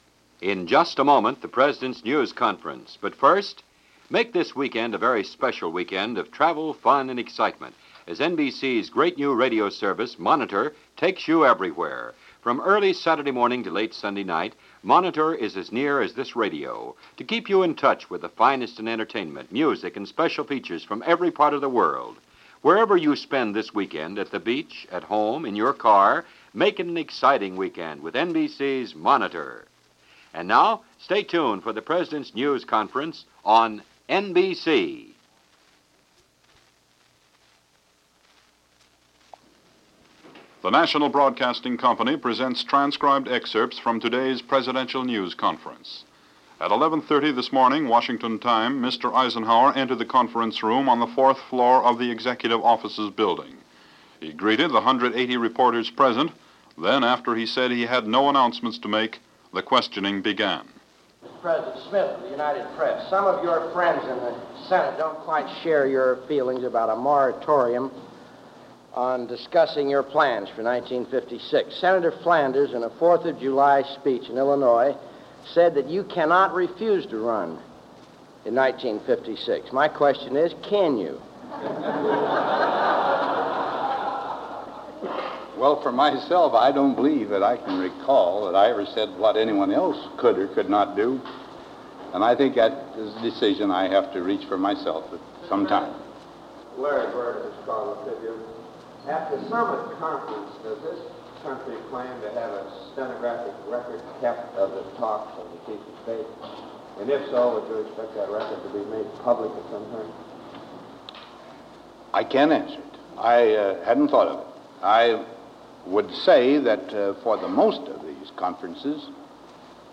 No Press Secretaries, no spokespeople, nobody running flack – President Eisenhower taking and answering questions on a variety of issues.